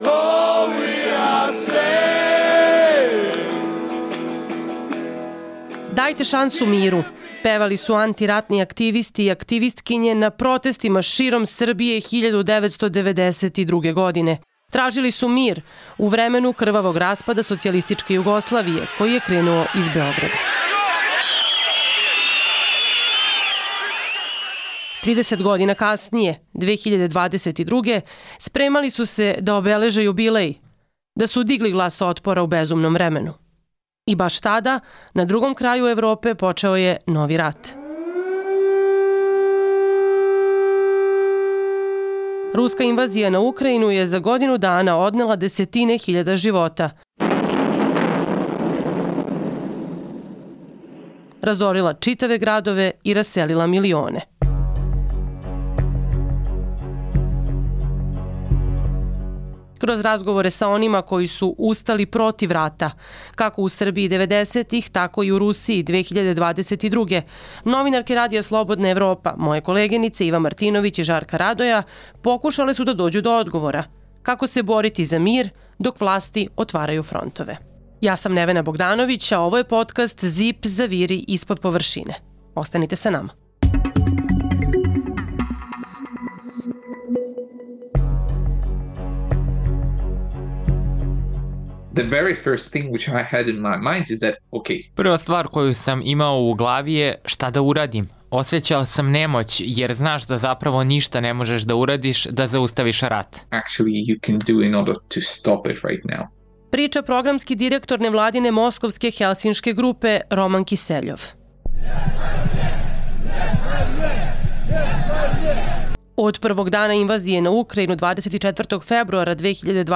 Kroz razgovore sa onima koji su se protivili ratu, kako u Srbiji devedesetih, tako i u Rusiji 2022, podkast ZIP pokušava da odgovori na pitanje: kako se boriti se za mir dok vlasti otvaraju frontove?